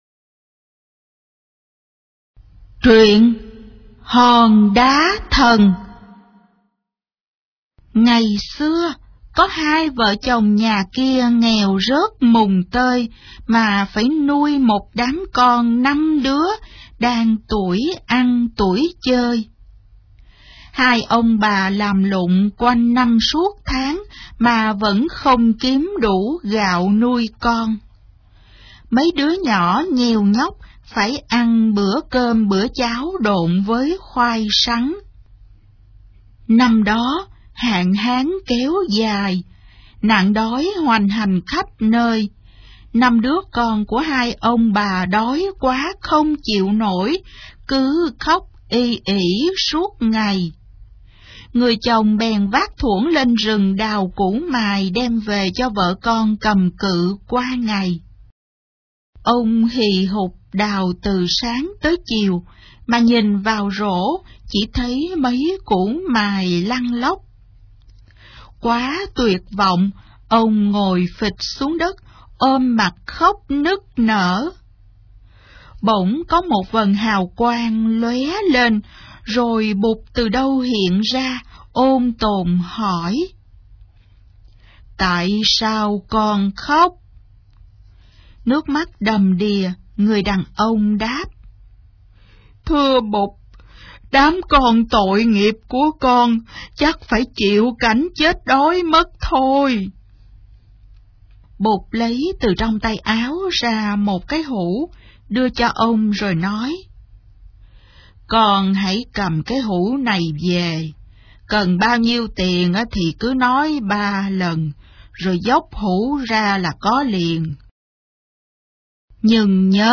Sách nói | 32_TRUYEN_CO_TICH_VN32